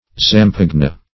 Search Result for " zampogna" : The Collaborative International Dictionary of English v.0.48: Zampogna \Zam*po"gna\, n. [It.]